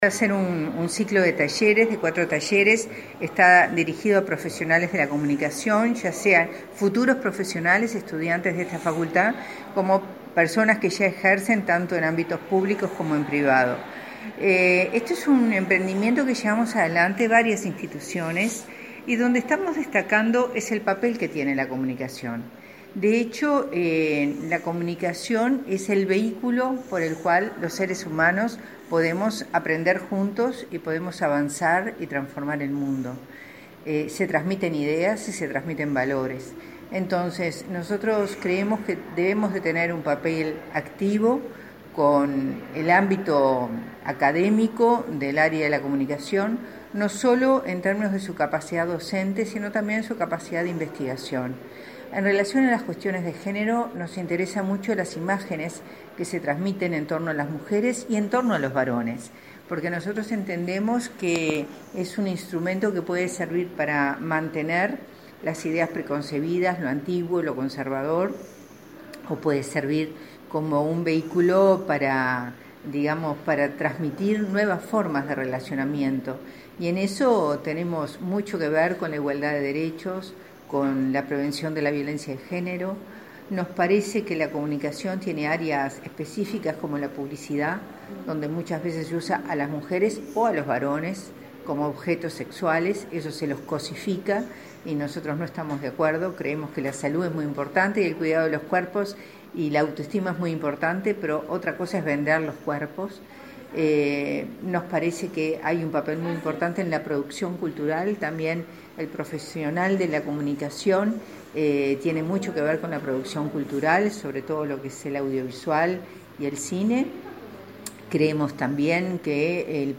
“El periodismo, la comunicación institucional y los profesionales de la comunicación de la industria audiovisual son responsables, también, de conseguir un mundo diferente, más amigable y consciente de los derechos de las personas”, subrayó la directora del Inmujeres del Mides, Mariella Mazzotti, en el Ciclo de Charlas sobre Comunicación con Perspectiva de Derechos, que se realizó este martes 14 en Montevideo.